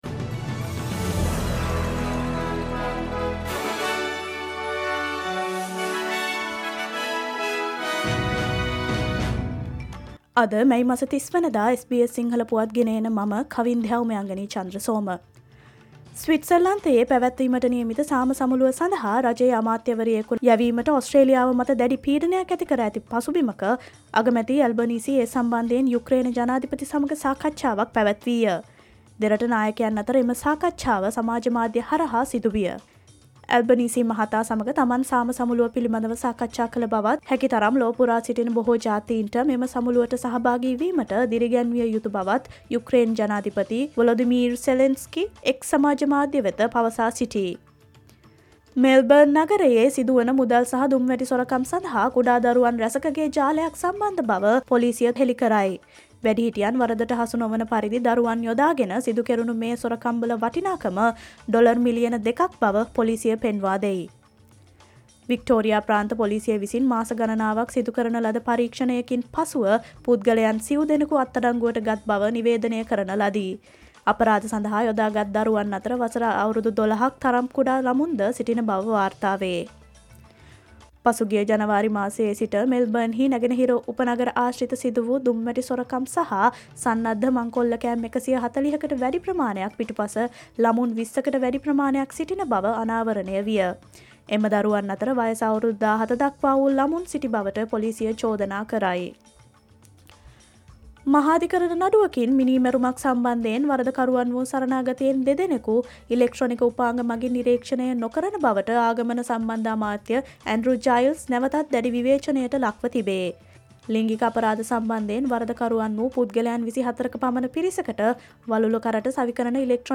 Australia's news in English, foreign and sports news in brief.
Listen, SBS Sinhala News Flash today Get the news of the day at a glance - Listen to the news release bringing SBS Sinhala Service on Mondays, Tuesdays, Thursdays and Fridays at 11am Australian time Share